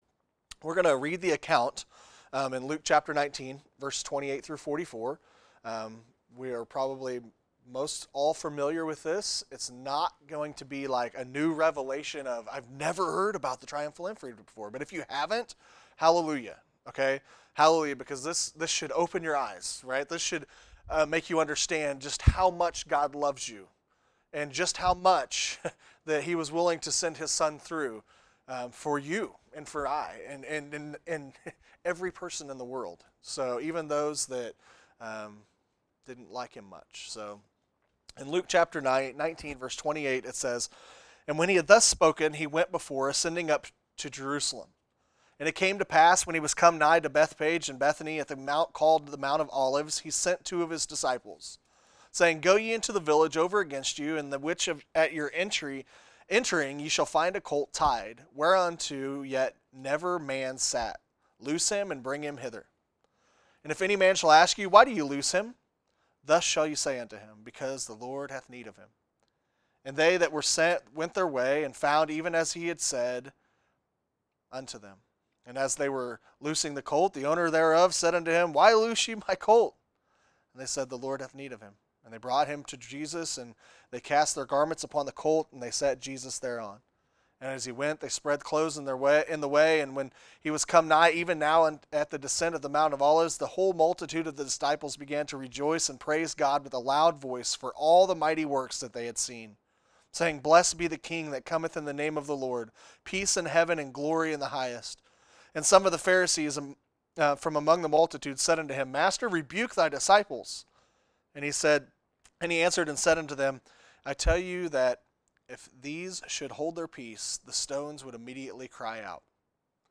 Sermons Archive - Page 13 of 52 - New Life Baptist Church of Clinton MO